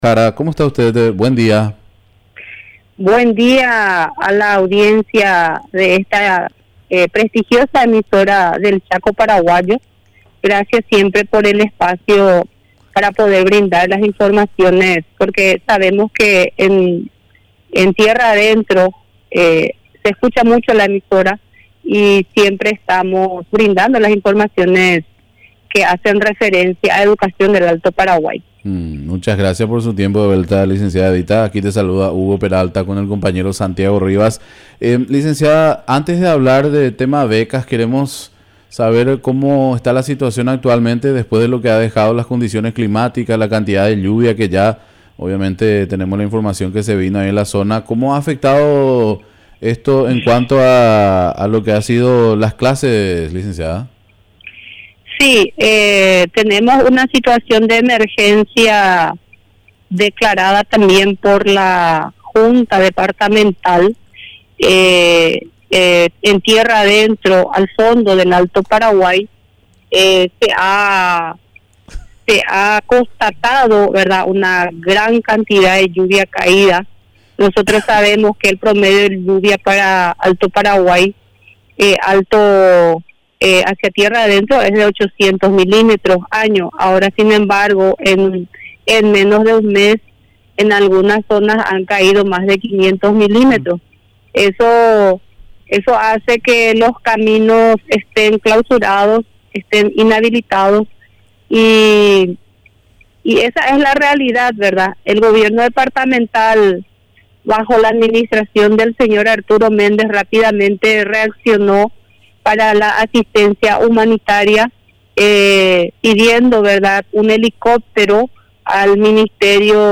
Entrevistas / Matinal 610 Desafíos en el sector educativo Apr 10 2025 | 00:22:43 1x 00:00 / 00:22:43 Subscribe Share RSS Feed Share Link Embed